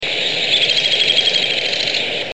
Downy Woodpecker (Picoides pubescens)
b. A rattle,
pick-ik-ik-ik-ik-ik, slightly slower than Hairy's, but notes quicken and descend in pitch toward end.   Soft and carries poorly for a woodpecker.
A high descending rattle or whinny series: ee ee ee ee ee ee (U).